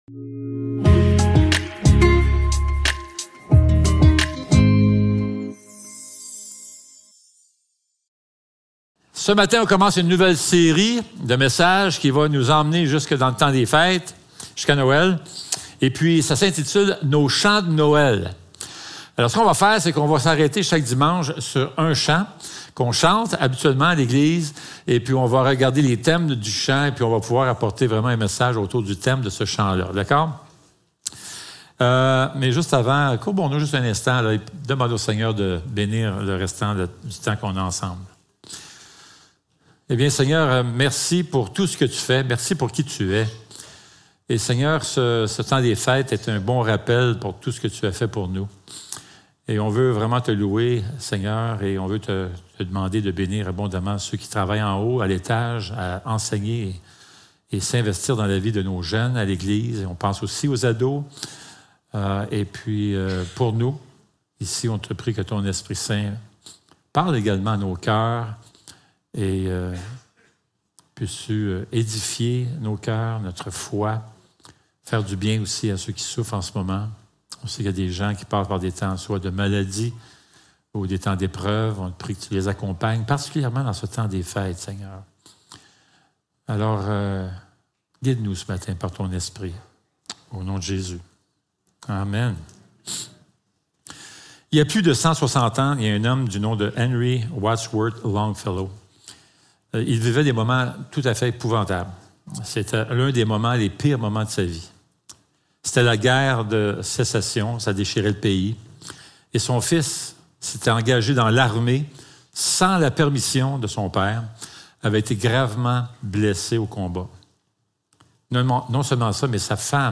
Luc 2.8-20 Service Type: Célébration dimanche matin Description